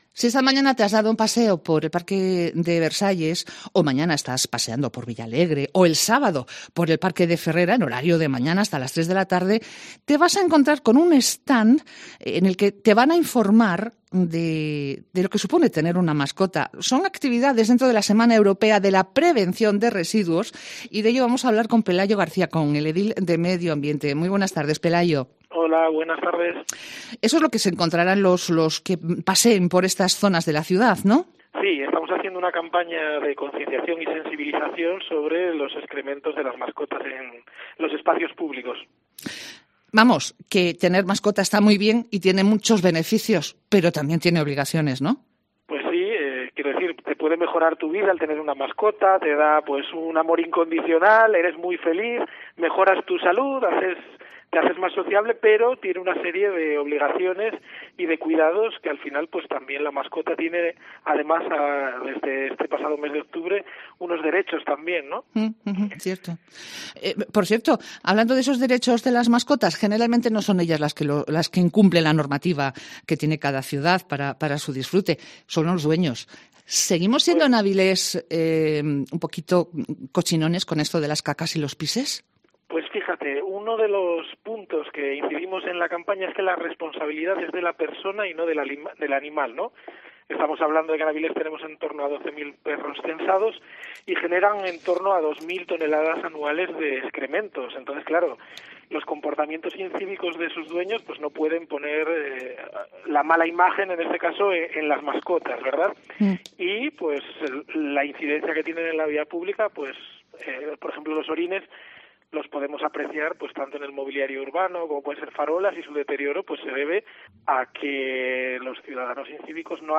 Entrevista con Pelayo García
Pelayo García, concejal de medioambiente, explicaba en COPE Avilés que afortunadamente las sanciones por incumplimiento de la ordenanza de tenencia de mascotas van disminuyendo y que, incluso, se está viendo como los propietarios de perros recriminan a aquellas personas incívicas su falta de educación.